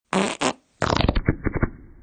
fart_mNI6G0U.mp3